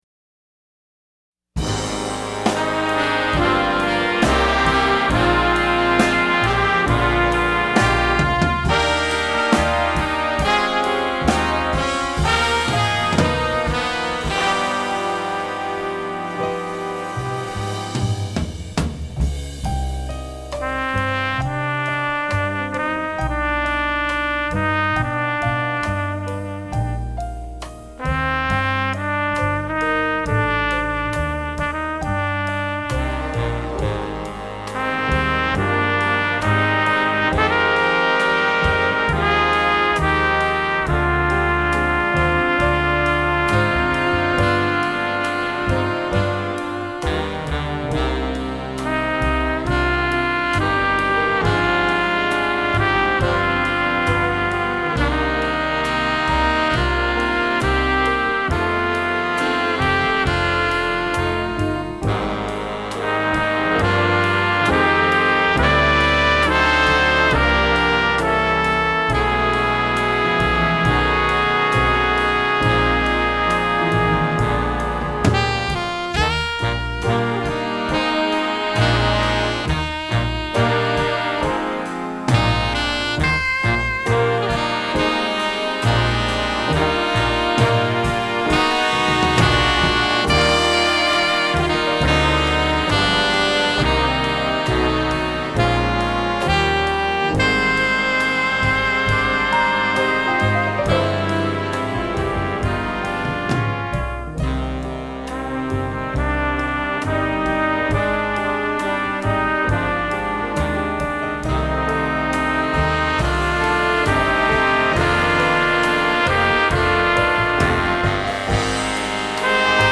Instrumentation: jazz band